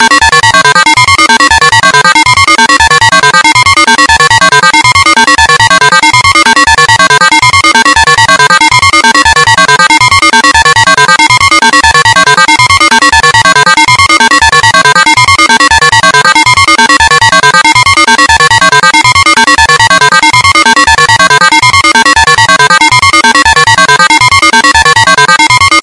计算机DTMF音调
描述：计算机DTMF音
Tag: flatulation poot 肠胃气胀 气体 船舶 空间 外星人 怪异 节拍 计算机 激光 噪声